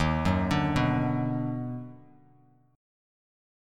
EbmM7#5 chord